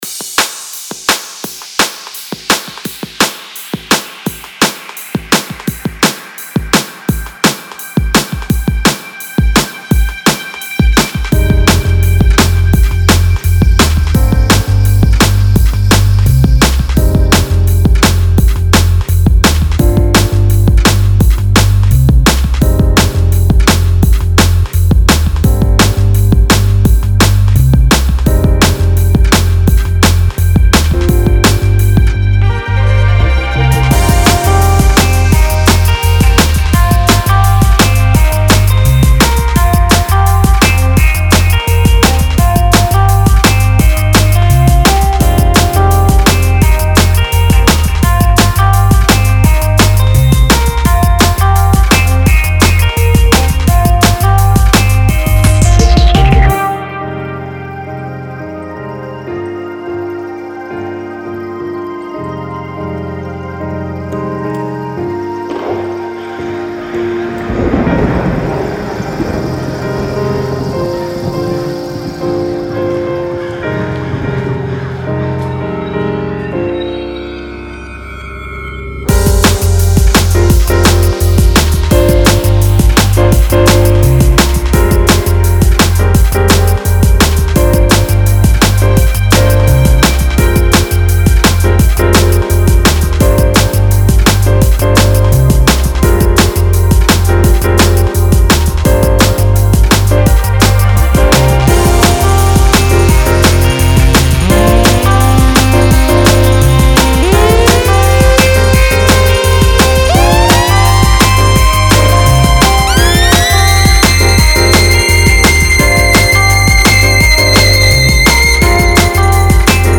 a combination of drum and bass and jazz